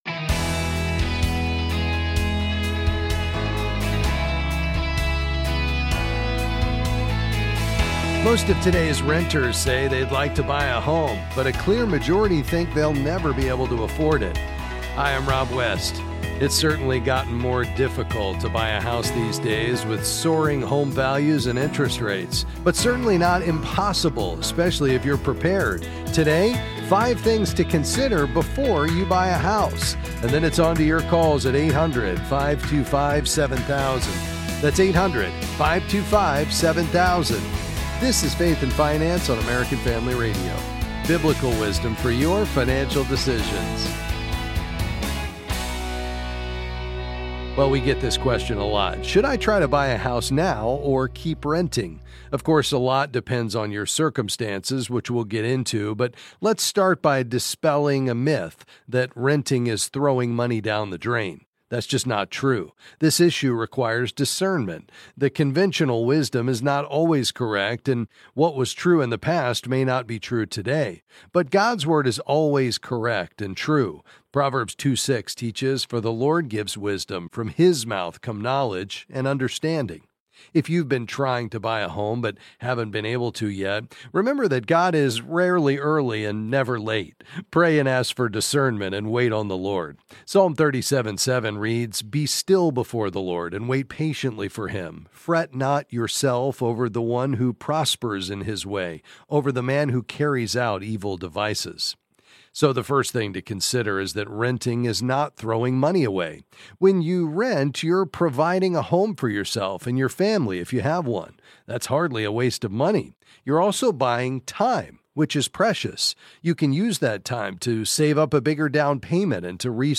Then he answers questions on various financial topics.